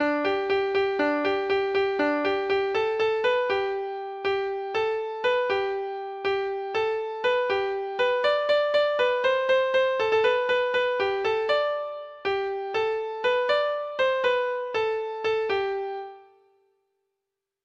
Treble Clef Instrument version
Folk Songs
note: halyard shantey